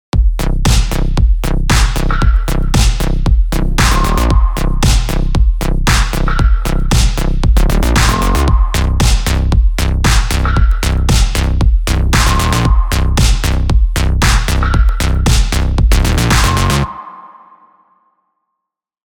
• Add organic saturation and richness to flat-sounding drums, instruments, and vocals
A faithful tape machine sound
KF_BabyAudio_Taip_AggroSynthBass_v1.mp3